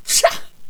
princess_attack1.wav